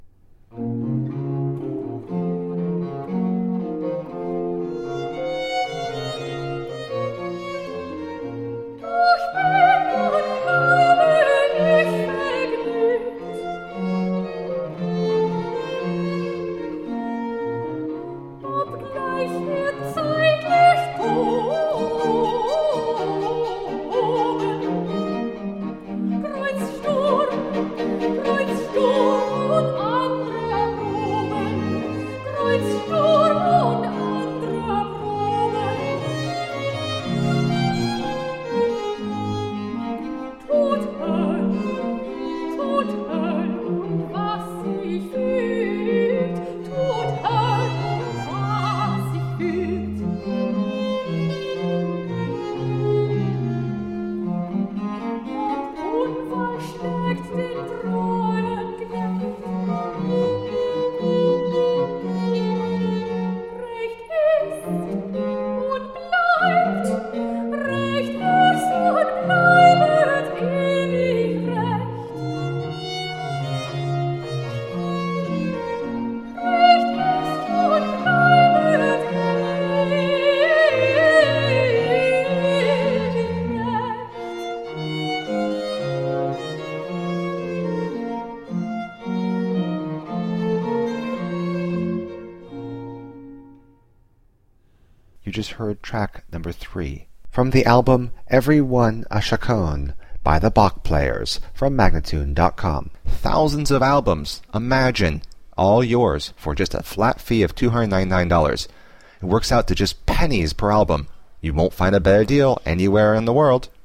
Baroque instrumental and vocal gems.